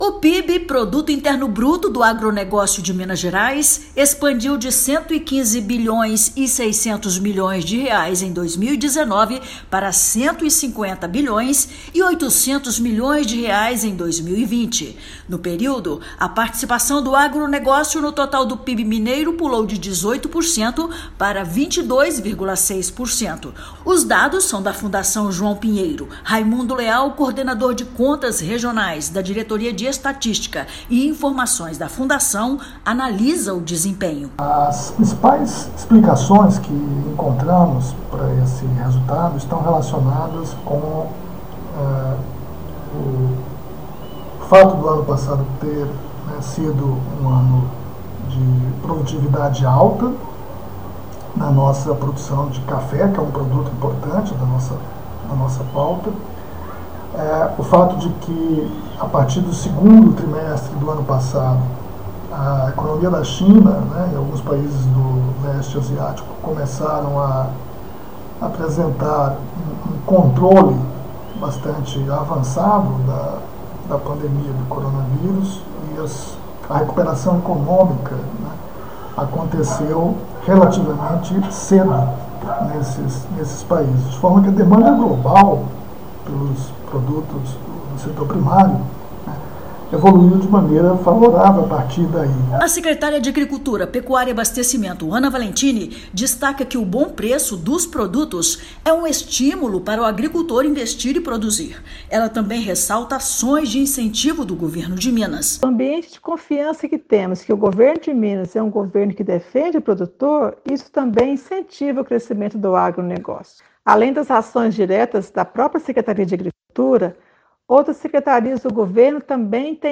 No período, a participação no total do PIB estadual evoluiu de 18% para 22,6%. Ouça matéria de rádio.